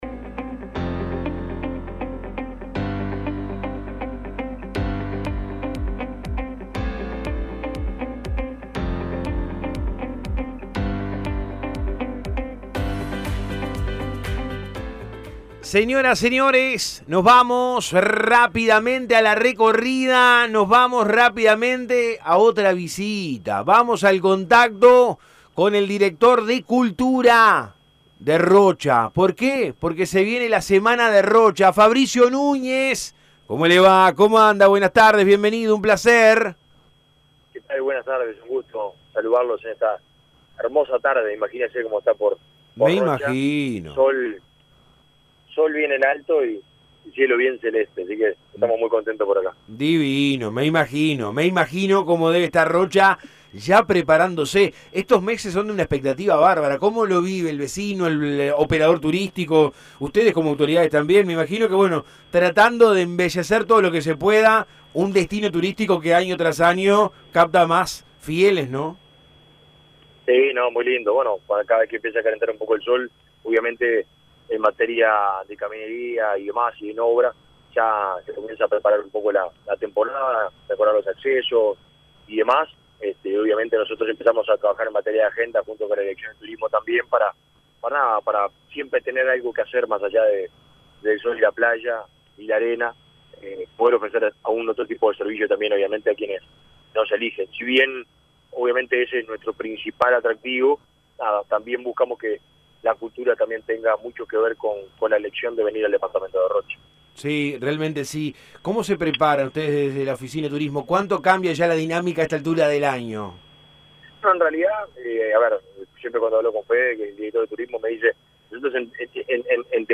En diálogo con Todo Un País el director de cultura departamental Fabricio Núñez repasó la grilla de músicos y contó las distintas actividades que habrá durante toda la Fiesta.